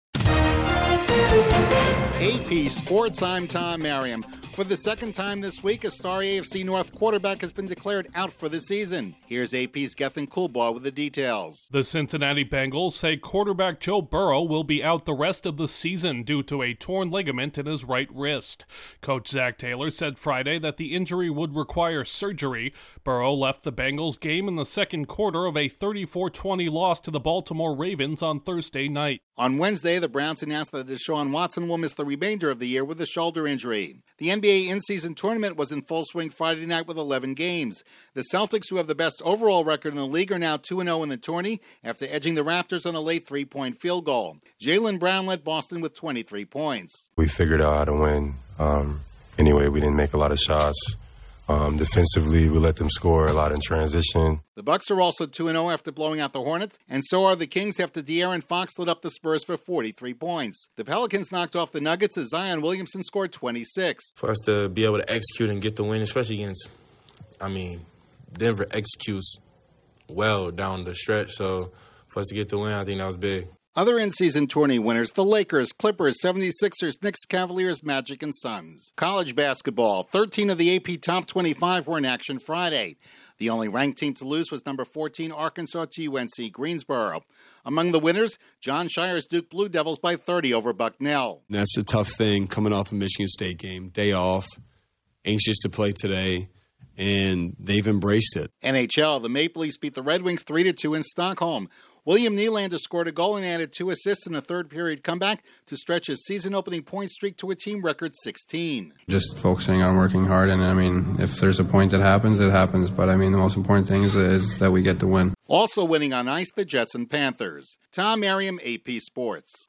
Joe Burrow’s season is suddenly over, the Celtics, Bucks, Lakers and Kings remain unbeaten in the NBA’s In-Season Tournament, 13 of 14 AP Top 25 college basketball teams win, and the Maple Leafs rally for a win in Sweden. Correspondent